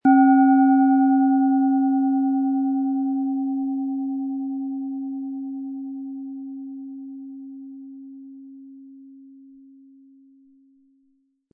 Planetenton 1
OM Ton
Wie klingt diese tibetische Klangschale mit dem Planetenton OM-Ton?
Ihre Klangschale wird mit dem beiliegenden Klöppel schön erklingen.
SchalenformBihar
MaterialBronze